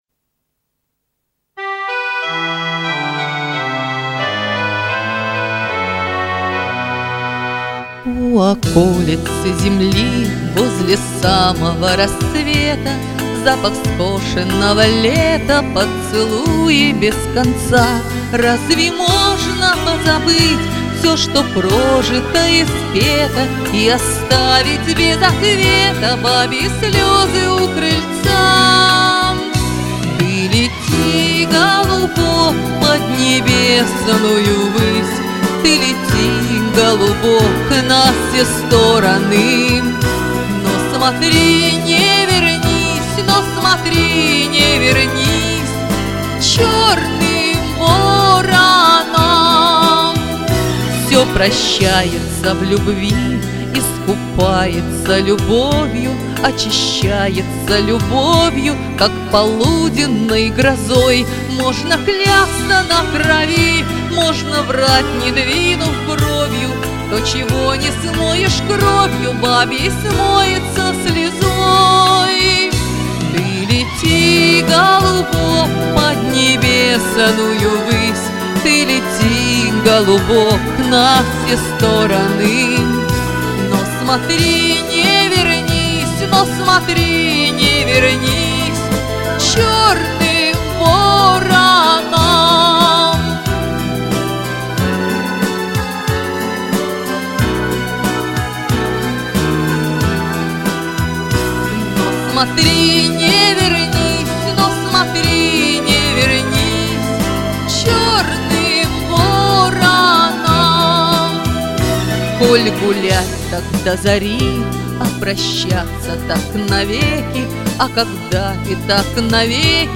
Обожаю твой вилюровый тембр голоса.